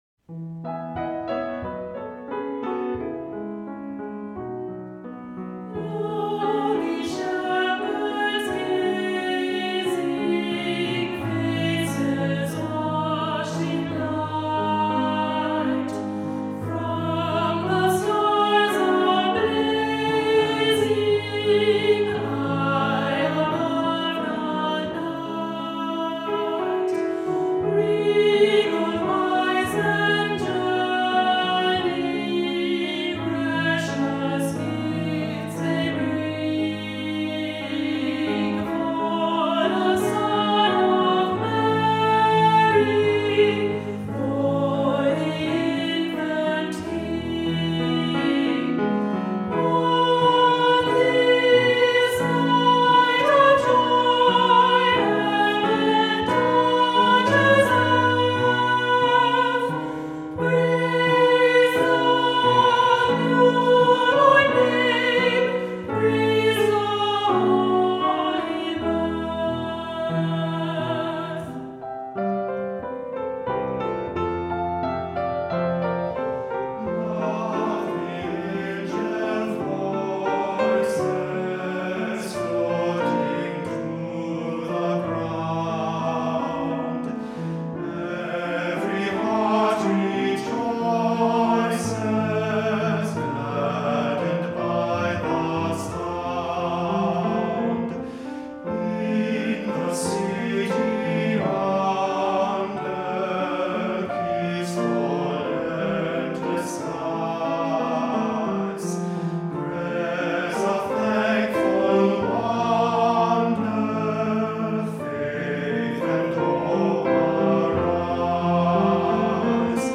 Voicing: 2-part Choir,Unison,Soloist or Soloists